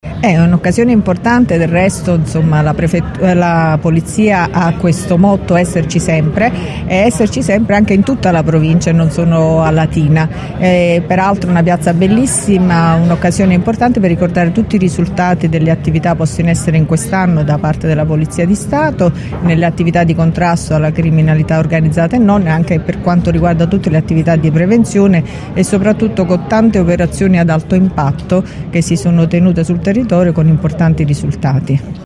“Il motto della Polizia è Esserci Sempre e essere qui, per questa occasione a Terracina, è una scelta giusta. La Polizia è presente su tutto il territorio della provincia”, ha detto la prefetta di Latina Vittoria Ciaramella che al suo arrivo, ha voluto sottolineare anche la bellezza della location.
prefetta-festa-polizia.mp3